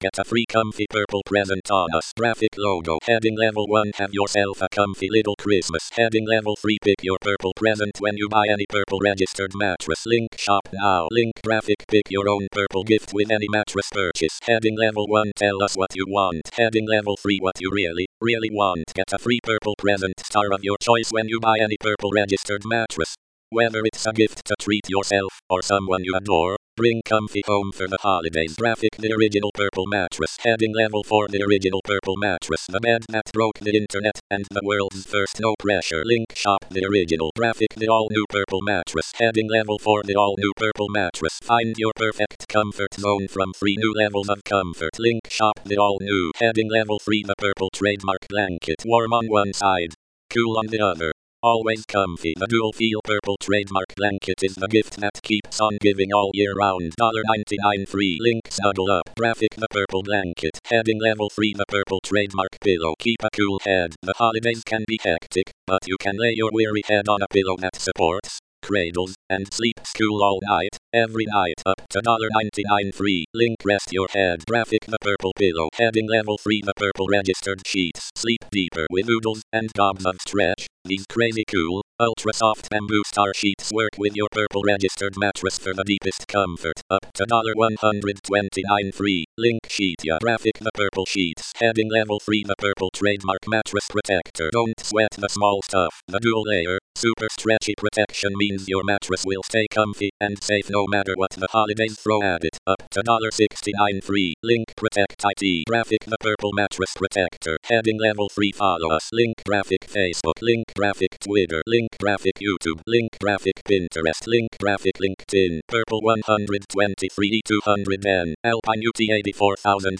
Text to voice recording and transcript for hearing impaired.